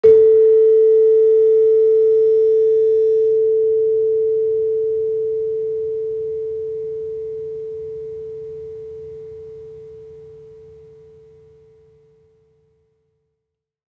Gamelan Sound Bank
Gender-1-A3-f.wav